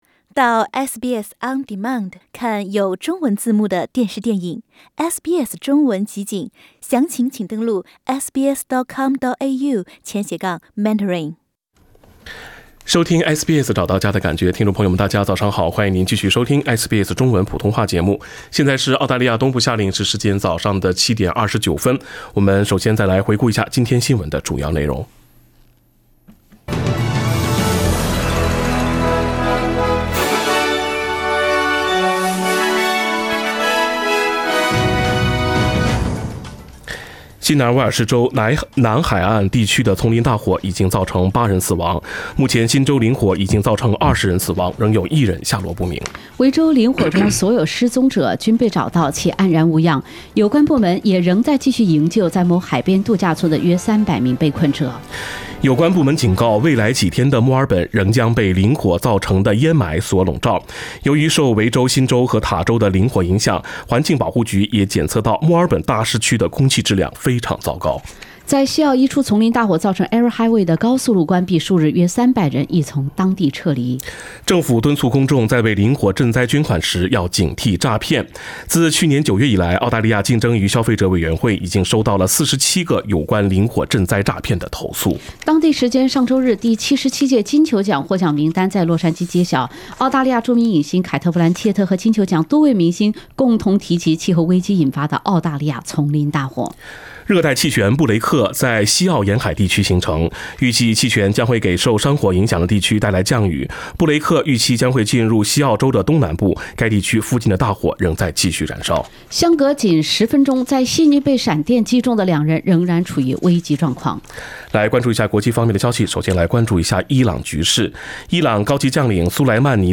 SBS早新闻（1月7日）